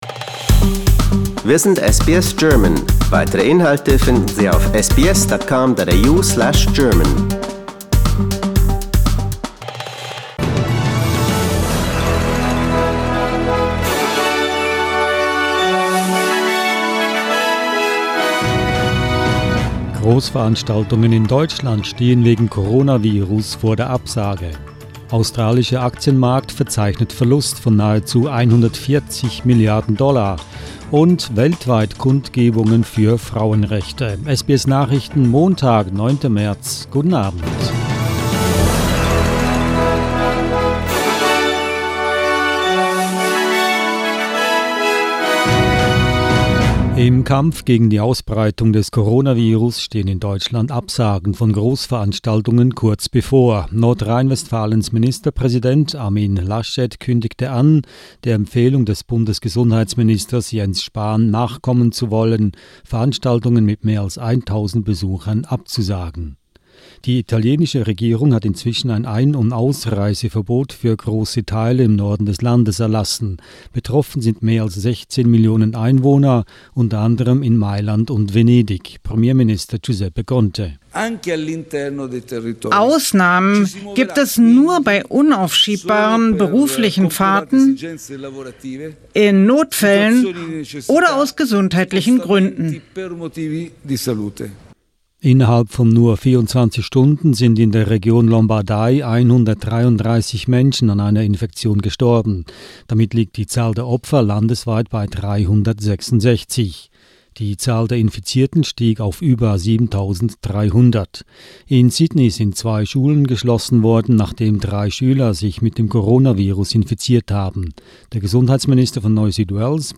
SBS Nachrichten, Montag 09.03.20